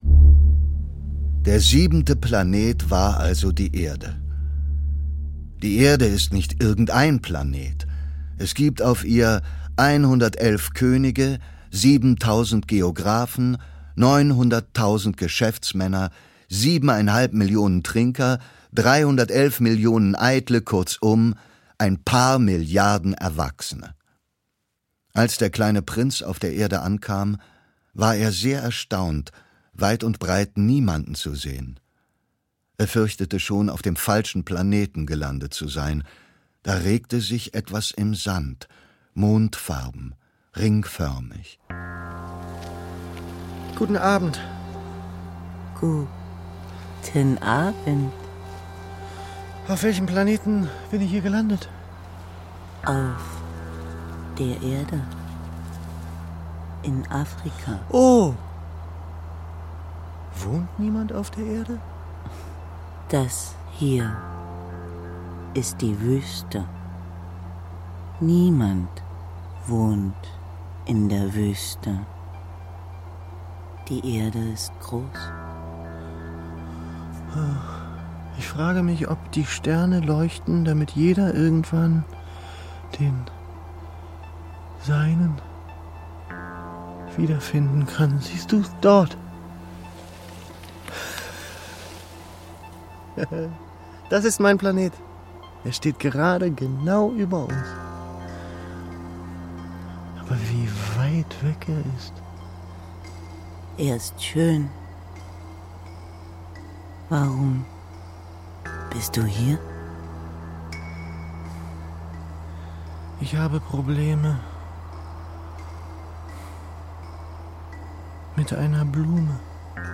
• Longlist DHP 2017 (Bestes Kinderhörspiel)
Leseprobe
Die flirrenden Soundscapes des Komponisten Carsten Nikolai alias „alva noto“ und des Berliner Electronic-Duos „Tarwater“ untermalen die Geschichte mit weichen Pastelltönen. Ein großes Bilderbuch der kleinen Philosophien mit großartiger Stimmung und Stimmen.“